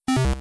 pc_off.wav